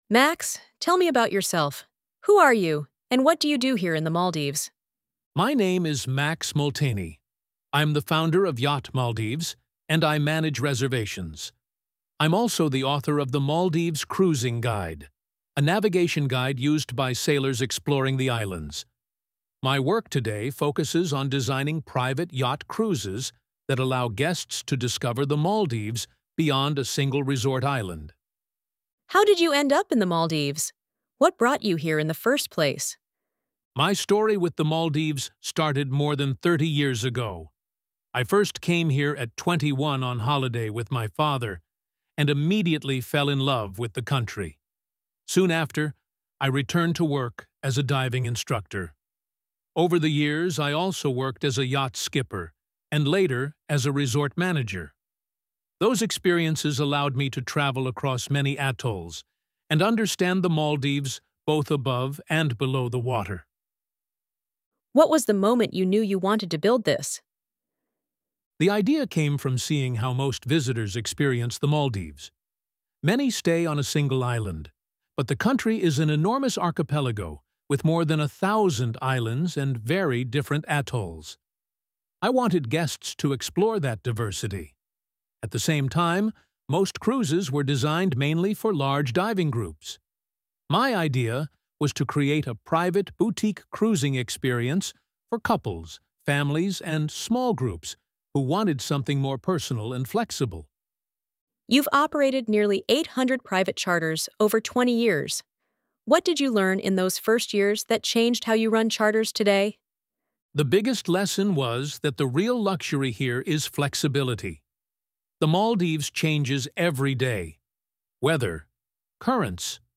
Per chi preferisce ascoltare, l’intervista completa è disponibile anche in versione audio in inglese narrata dall’intelligenza artificiale.